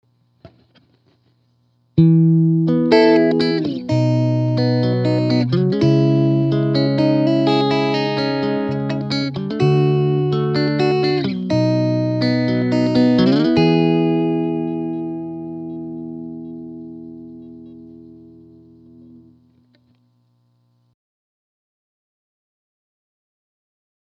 Clean (Squier Classic Vibe Tele 50’s)
2. Mic angled along speaker cone, 1″ off the grille cloth.
champ_rec_clean_ang.mp3